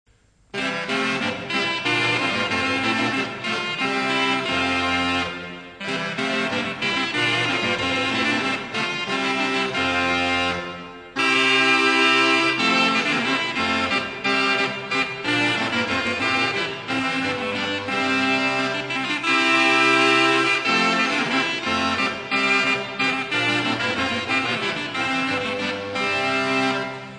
CROMORNE
Quatuor de cromornes
Convivium musicum